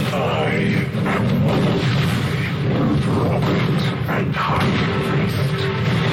Dungeon Siege 3 Boss Shouts....